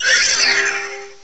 cry_not_cosmog.aif